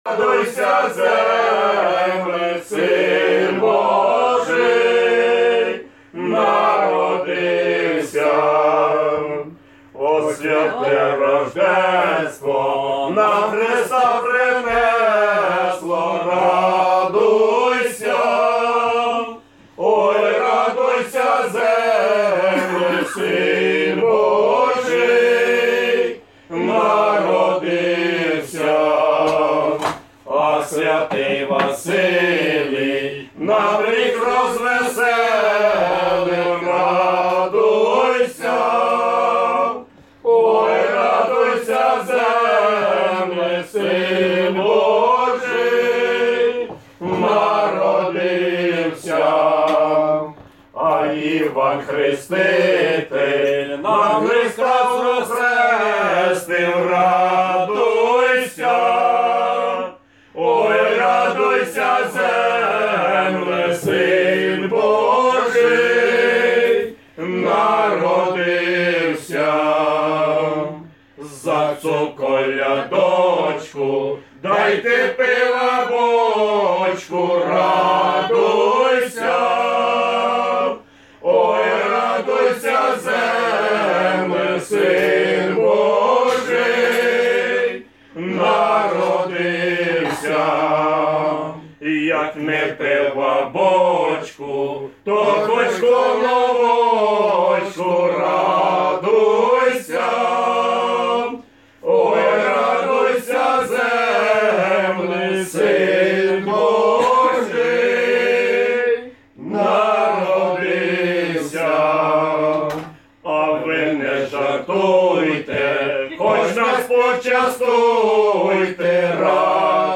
Ukrainian village Christmas carol